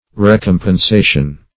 Recompensation \Re*com`pen*sa"tion\ (r?*k?m`p?n*s?"sh?n), n.